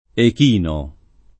echino [ ek & no ] s. m.